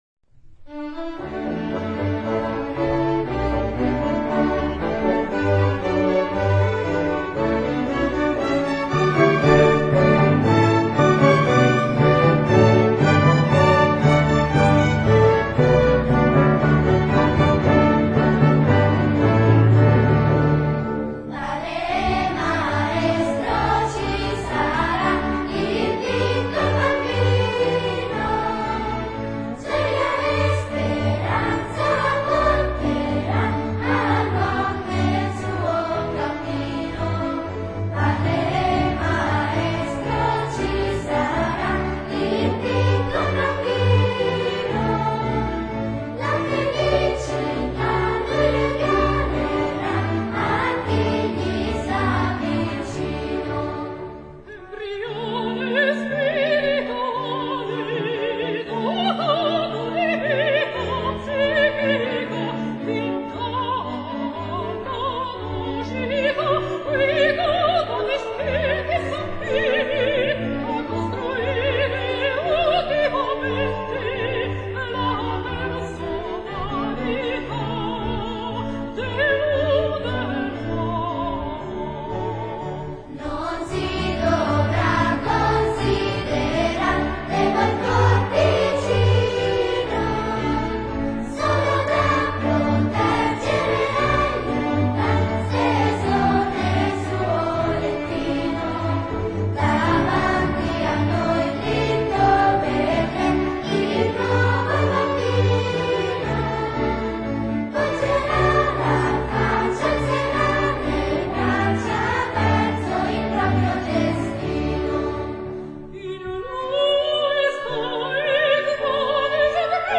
Teatro Comunale di Chiaravalle (AN)
(corale in rondò per soprano, coro di bambini e orchestra)